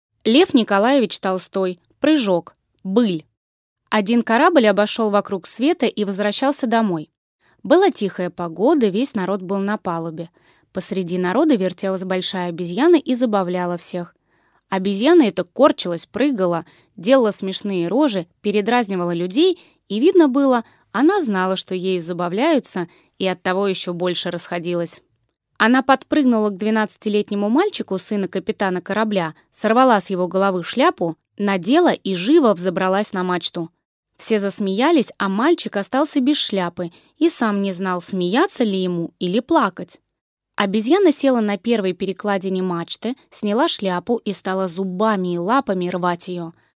Аудиокнига Прыжок | Библиотека аудиокниг